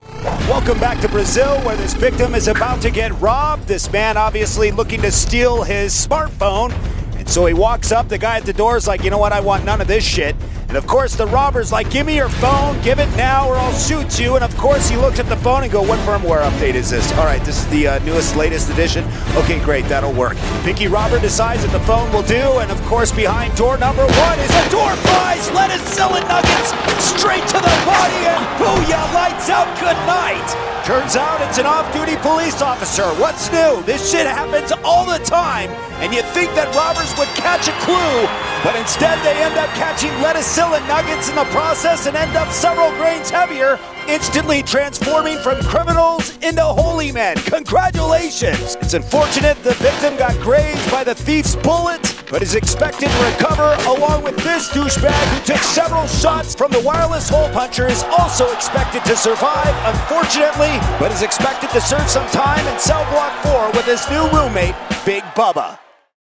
Robber Scores a Door Prize - Commentary